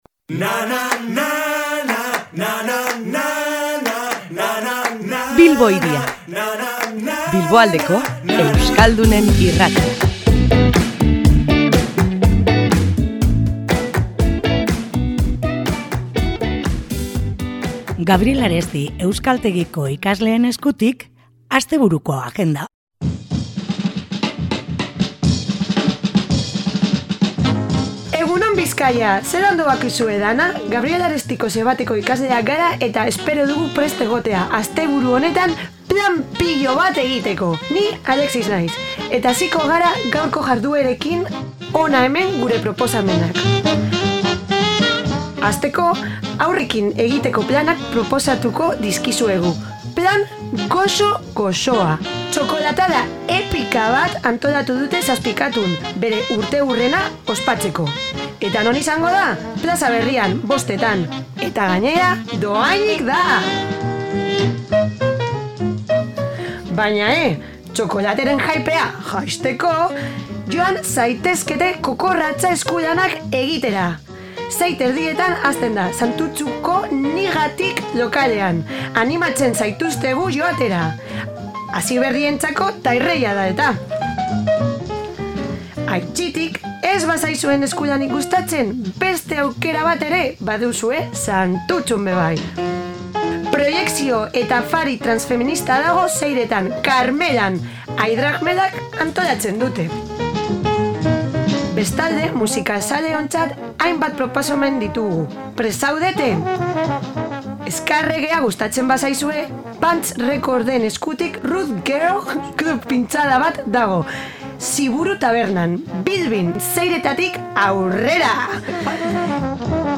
Gaur, Gabriel Aresti euskaltegiko ikasleak izan ditugu Bilbo Hiria irratian, eta haiek ekarritako proposamenekin astebururako plan erakargarriak ekarri dizkigute. Musika, antzezkia eta aisialdirako hainbat plan ekarri dizkigute.